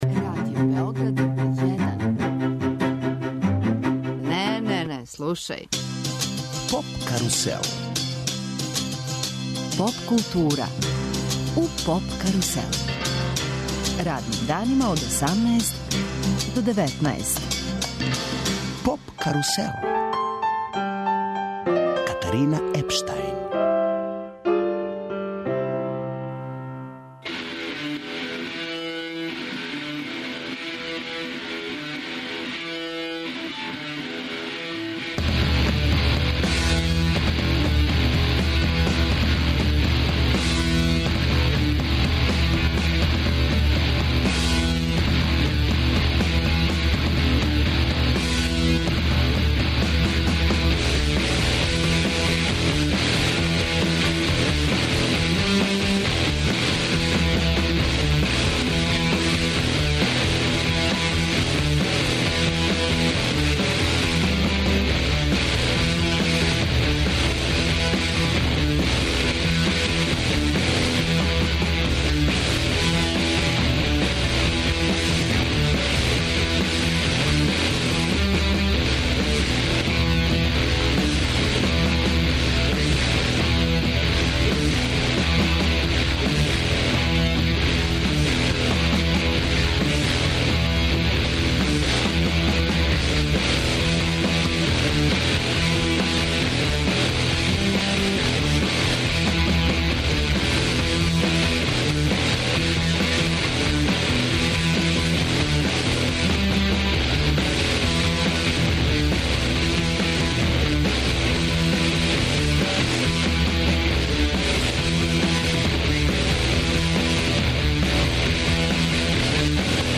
Радио Београд 1 и ове године уживо реализује емисије са фестивала Eurosonic, из Холандије. Eurosonic Noorderslag је музички фестивал посвећен изградњи европске поп сцене.